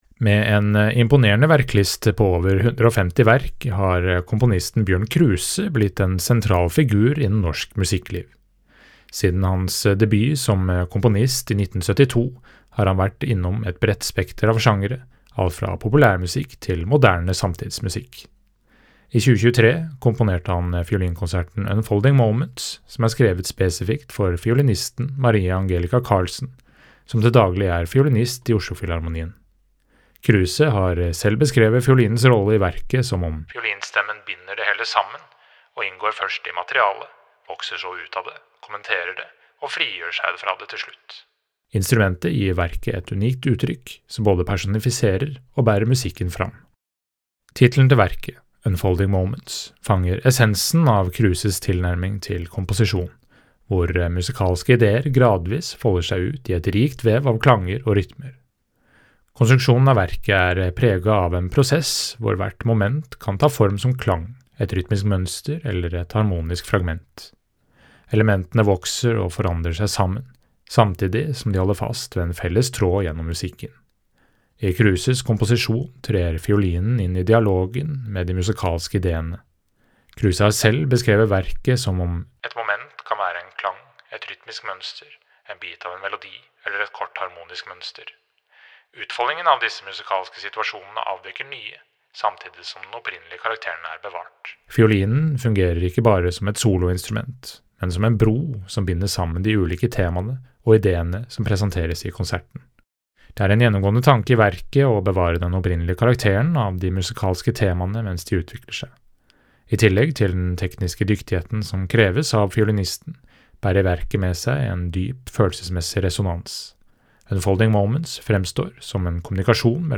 VERKOMTALE